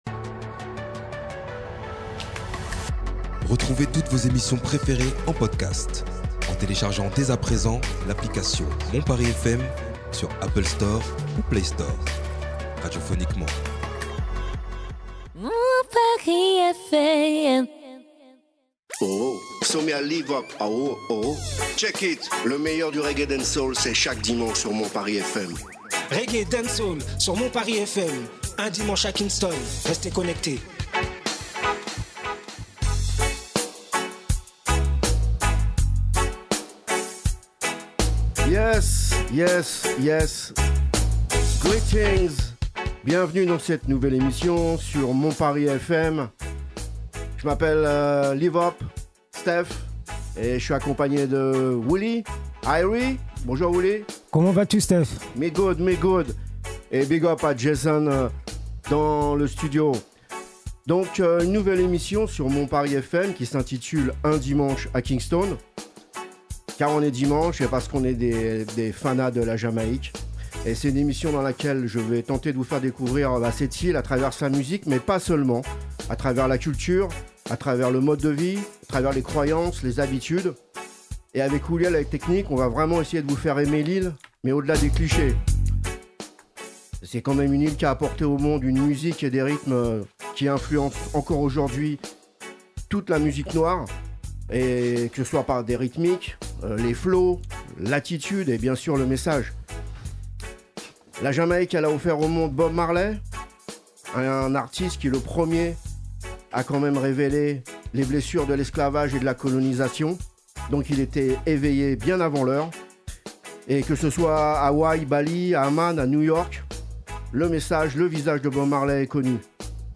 Partez à la découverte de la musique et surtout la culture et dela vie jamaïcaine avec une sélection musicale de premier choix, des news, des chroniques pour vous montrer un visage réaliste et rythmée de la culture jamaïcaine